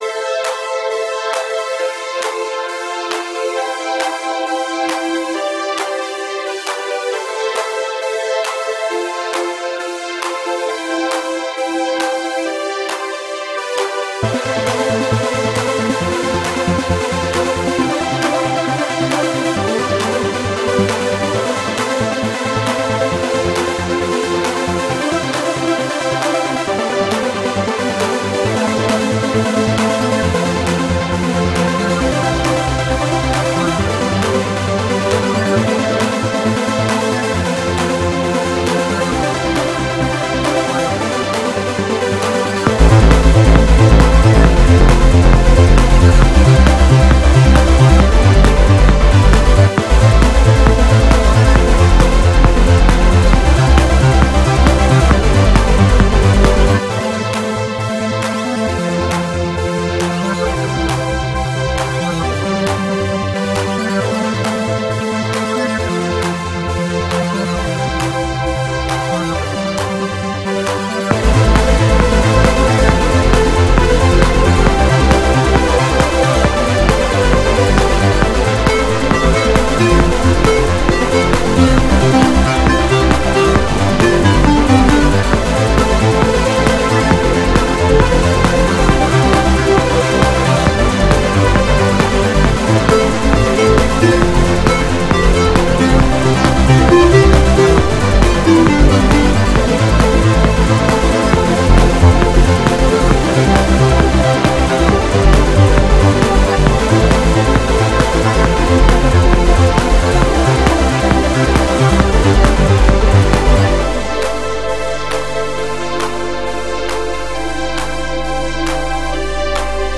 Style: Techno/Trance